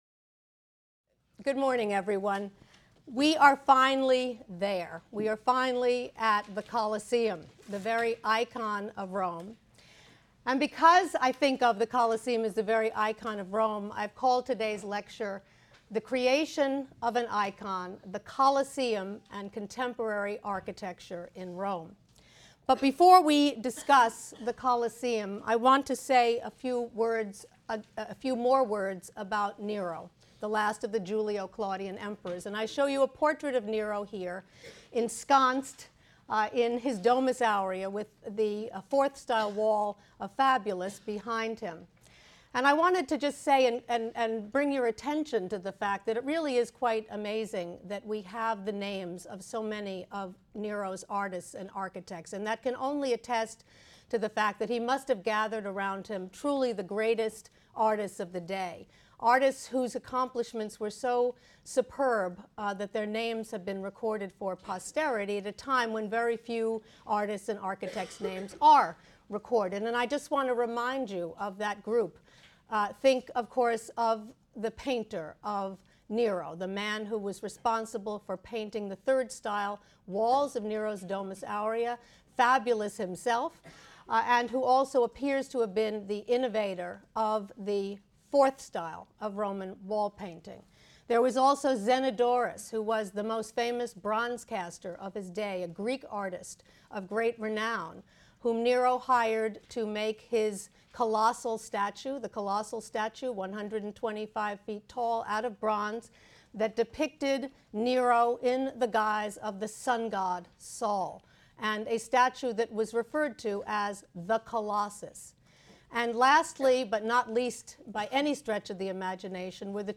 HSAR 252 - Lecture 12 - The Creation of an Icon: The Colosseum and Contemporary Architecture in Rome | Open Yale Courses